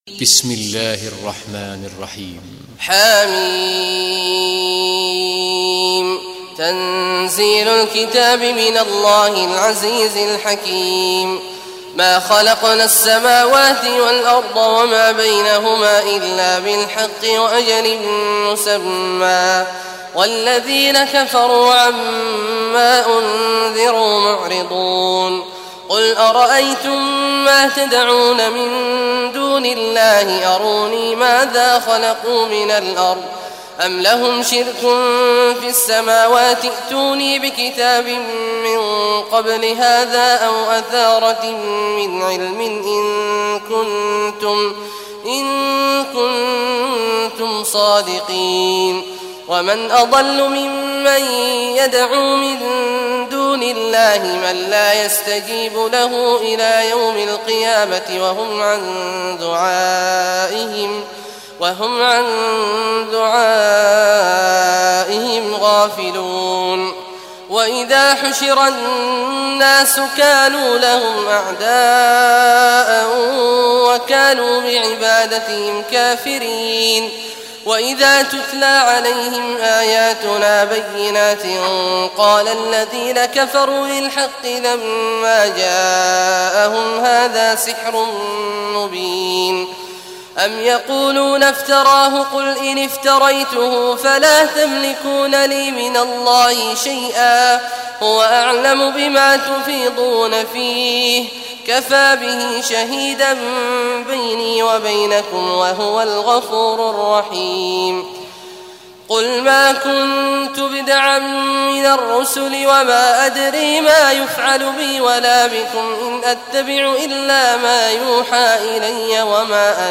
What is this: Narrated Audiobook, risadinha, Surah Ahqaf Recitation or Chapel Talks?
Surah Ahqaf Recitation